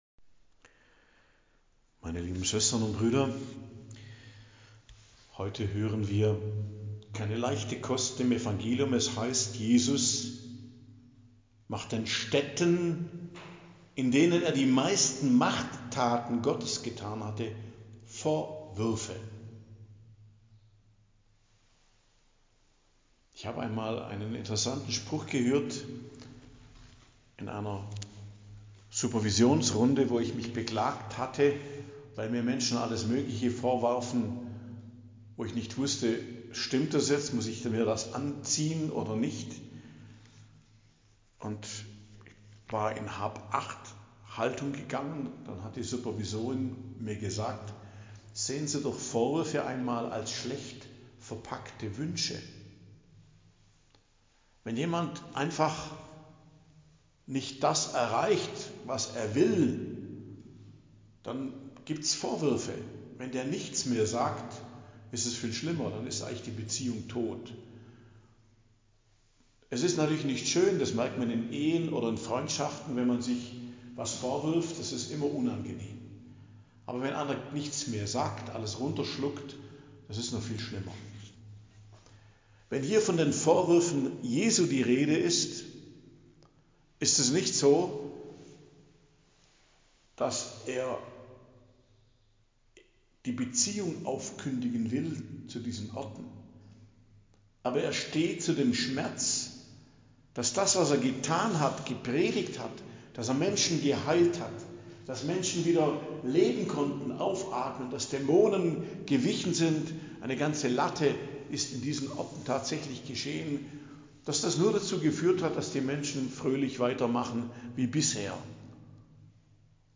Predigt am Dienstag der 15. Woche i.J., 15.07.2025